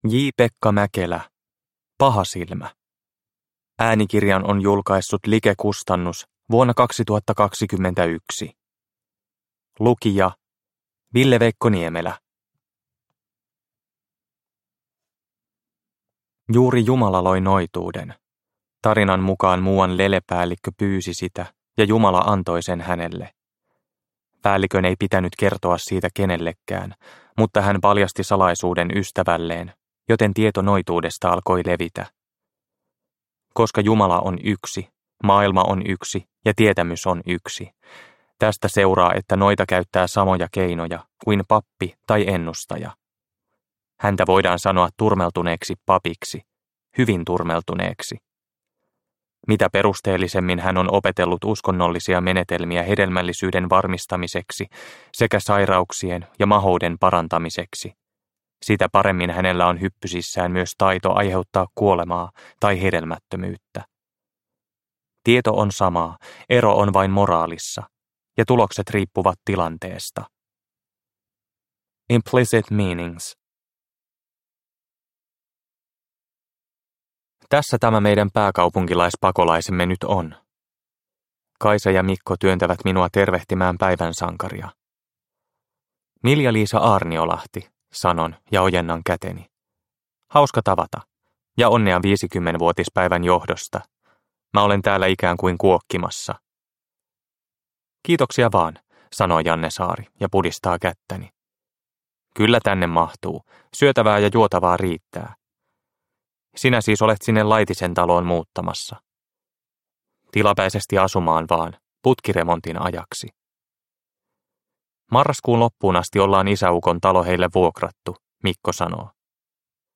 Pahasilmä – Ljudbok – Laddas ner